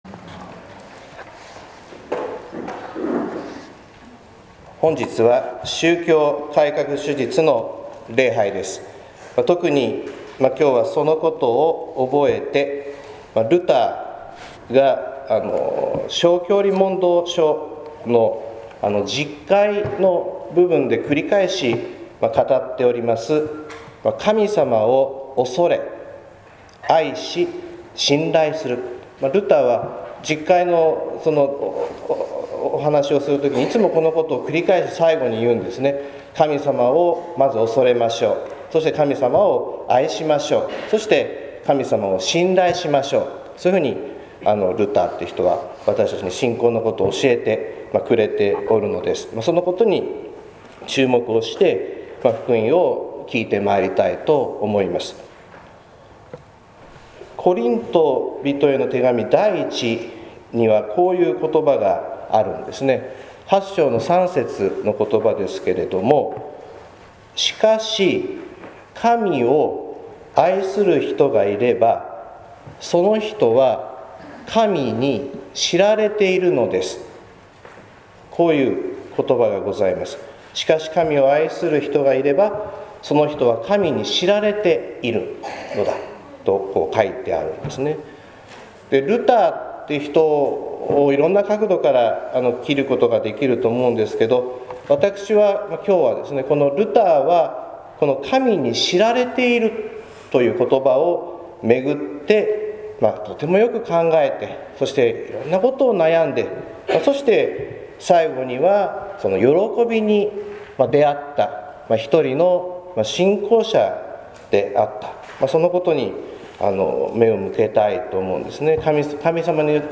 説教「神さまのめぐみ」（音声版）